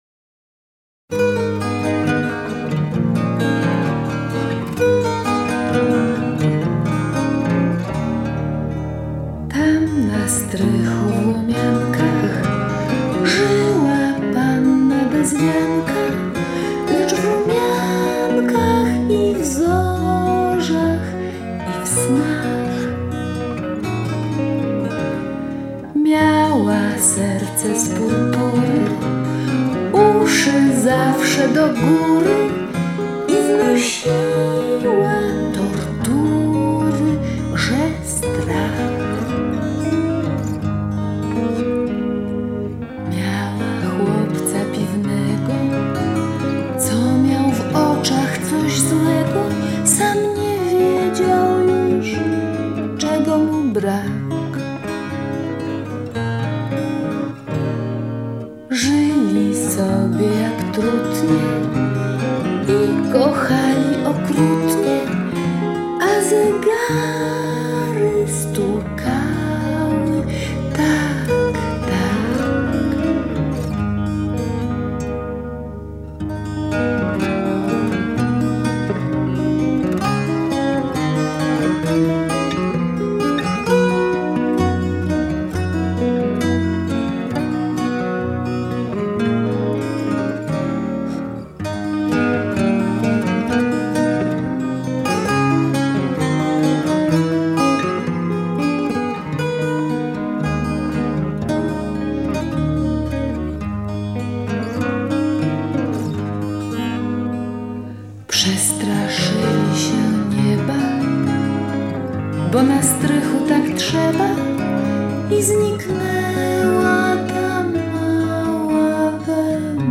Światowy Dzień Poezji, 17 kwietnia 2009
Dom Kultury w Łomiankach